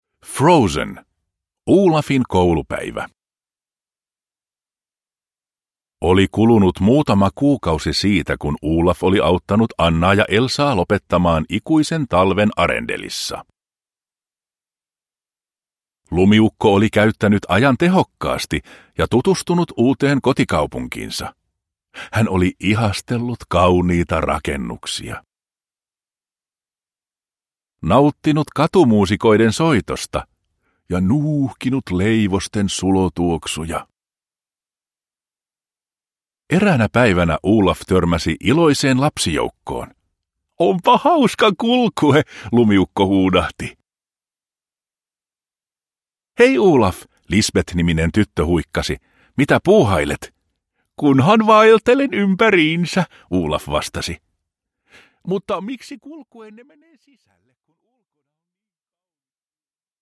Olafin koulupäivä – Ljudbok – Laddas ner